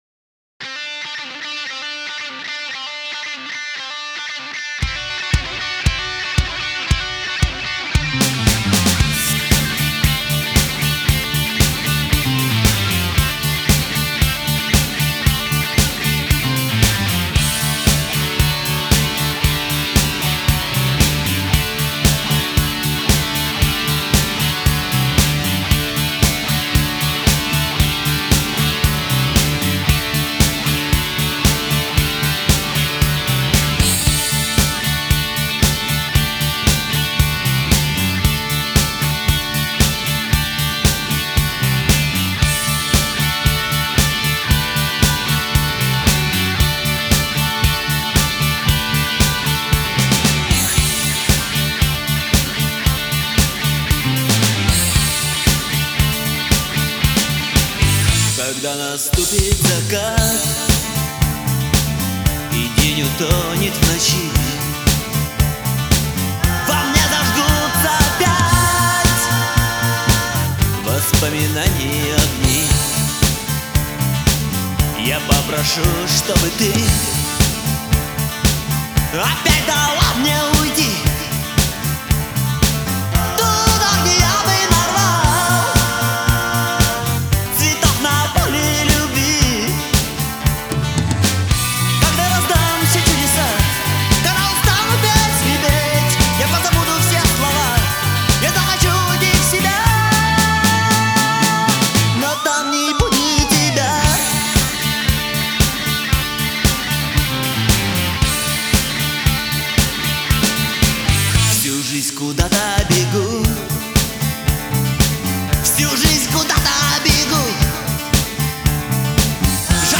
Альбом записан в студии
гитара, вокал
барабаны
бас